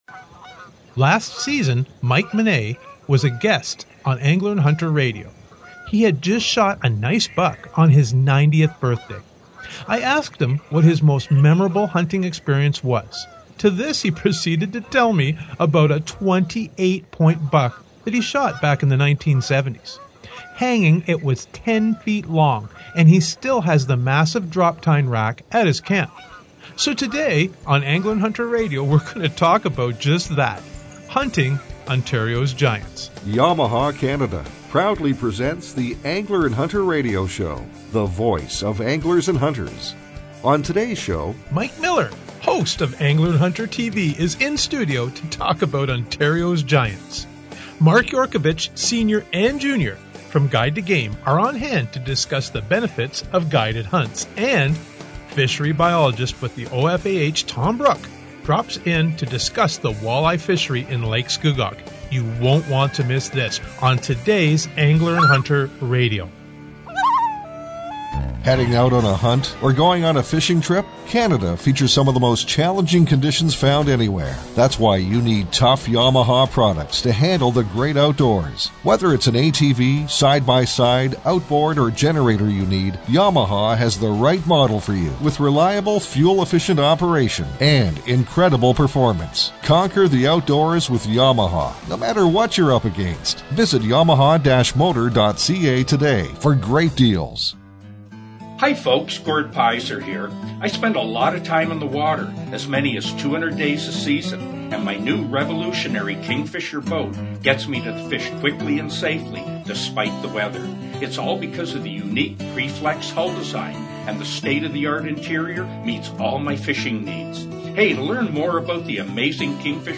is in studio to talk about Ontario’s giants.